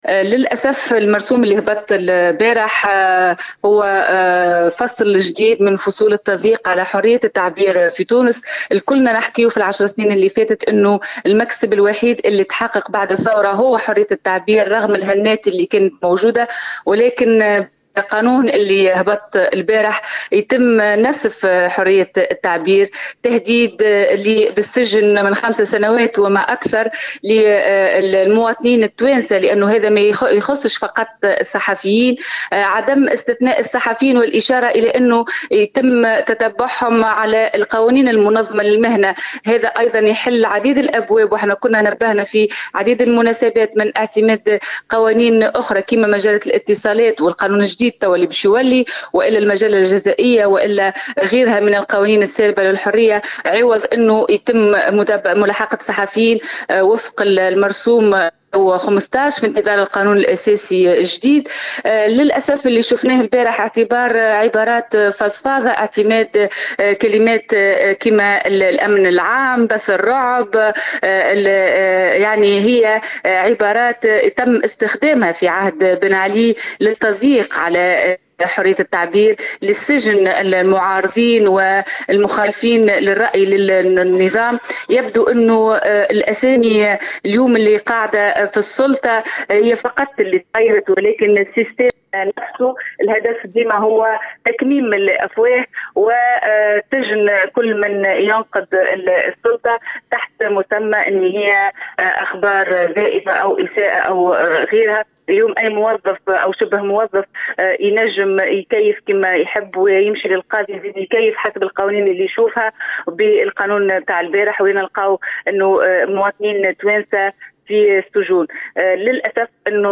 تصريح للجوهرة اف ام